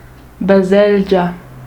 vor i un e als /ɟ/ (s i blybt stumm)   roh-sursilvan-grischun.ogg grischun(info) - ‚Graubünde‘